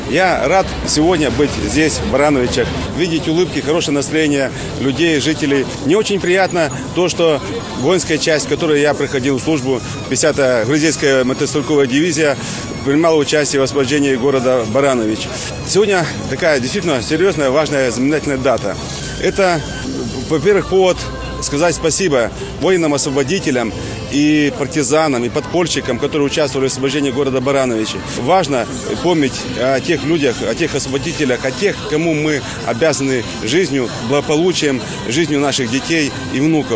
Этот знаменательный день – повод еще раз сказать спасибо воинам освободителям, партизанам, подпольщикам. Важно помнить – кому мы обязаны жизнью, сказал помощник Президента Республики Беларусь по Брестской области Валерий Вакульчик, который сегодня посетил наш город.